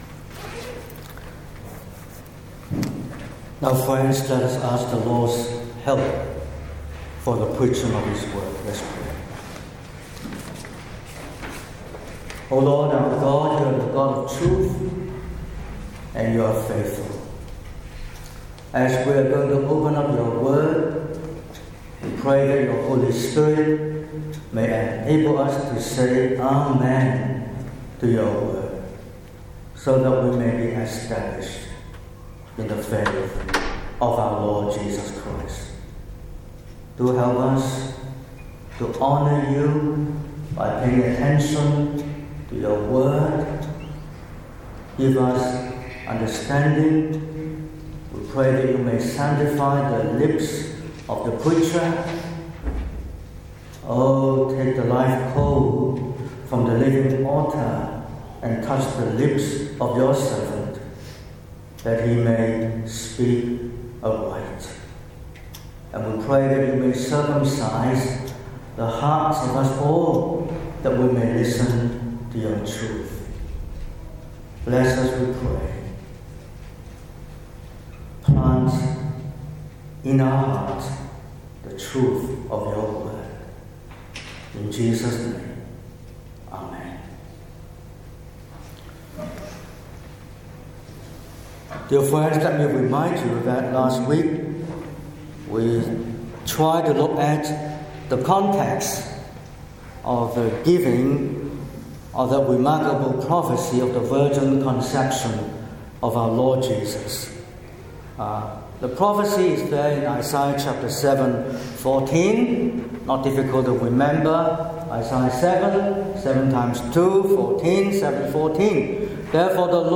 09/11/2025 – Morning Service: Whom do we trust?